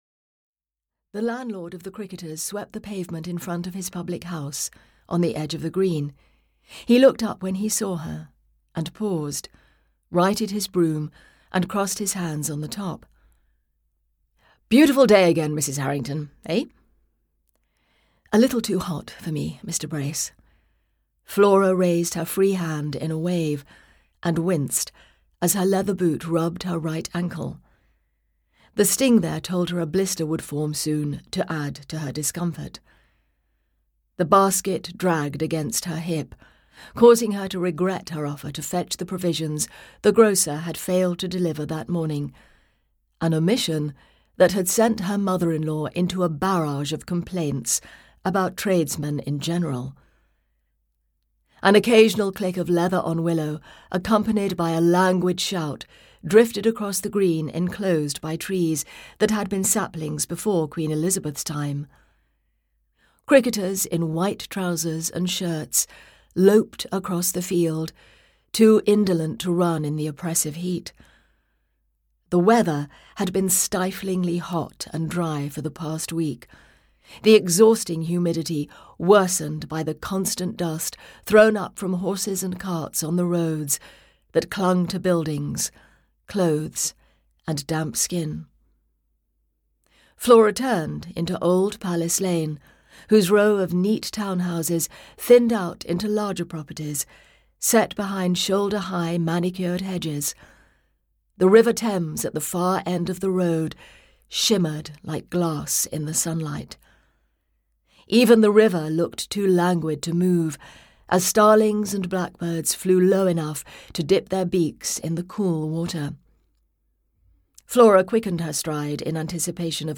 Death at the Abbey (EN) audiokniha
Ukázka z knihy